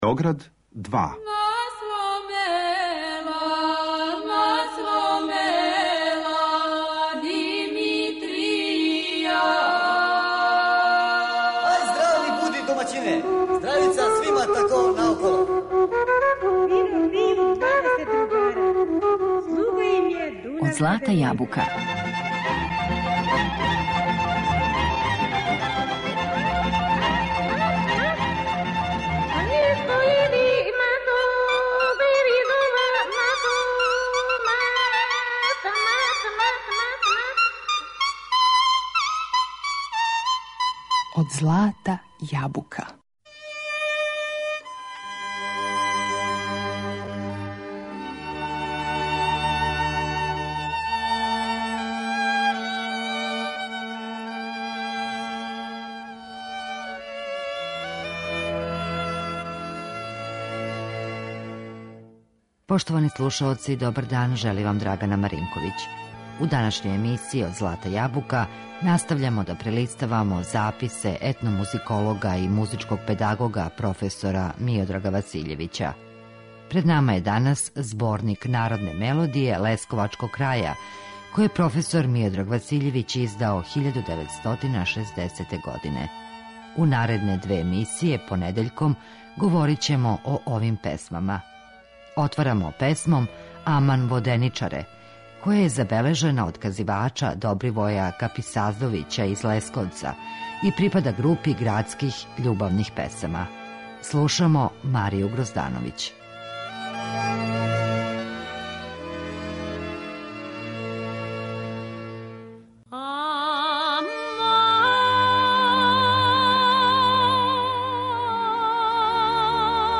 Народне мелодије лесковачког краја